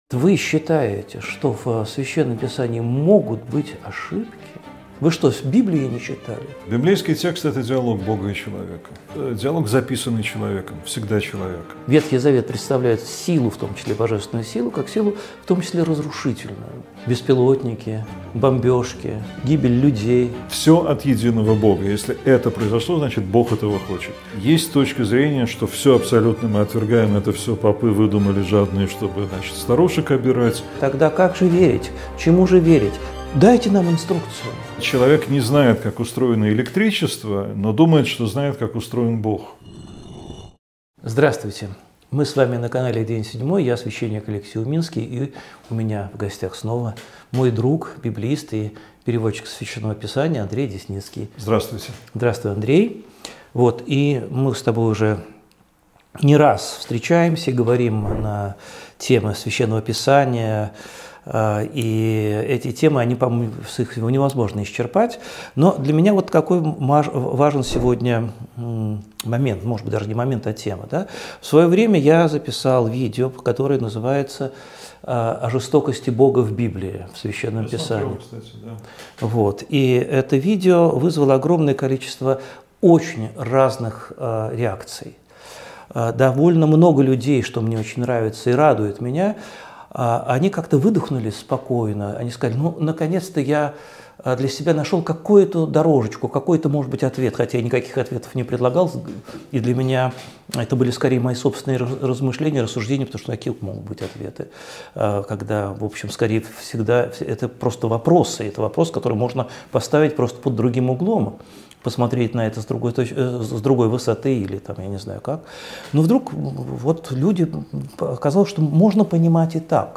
беседуют об ошибках понимания Евангелия.